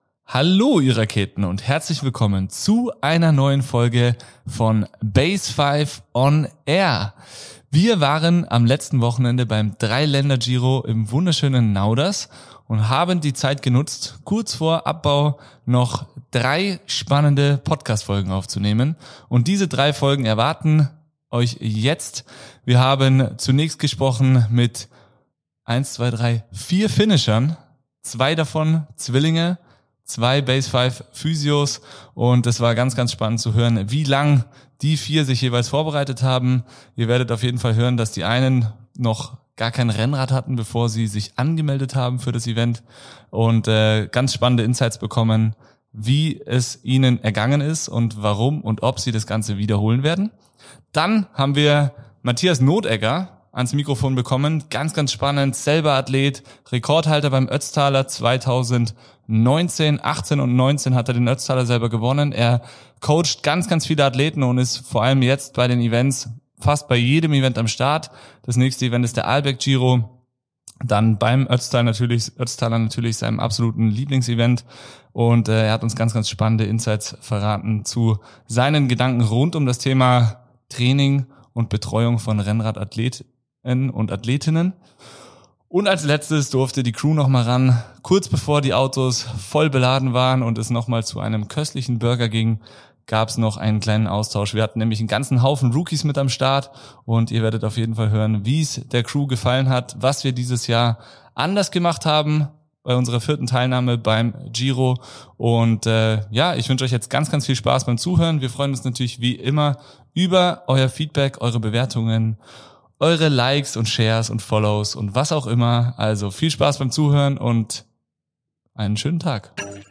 In dieser On-Event-Folge nehmen wir euch mit hinter die Kulissen des Radsport-Klassikers in Nauders.